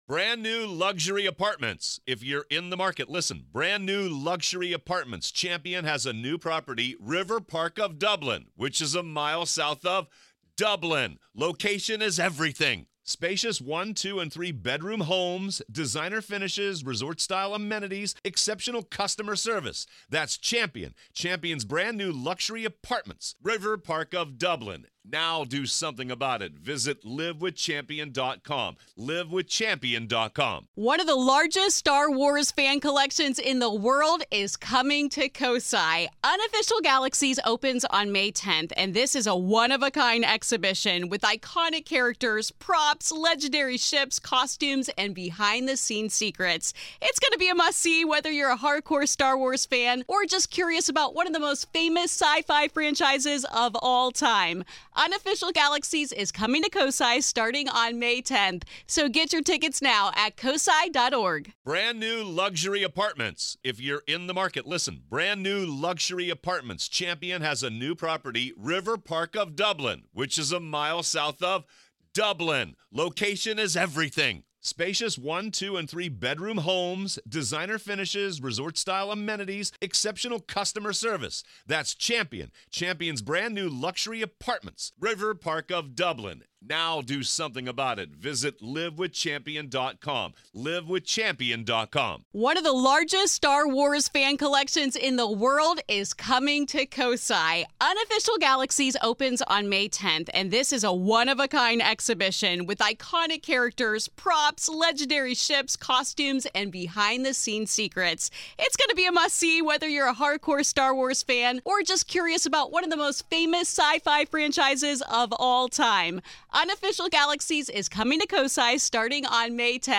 What caused this once peaceful family home to descend into chaos so quickly—and is it possible that the evil was there all along, just waiting for its time? This is Part Two of our conversation.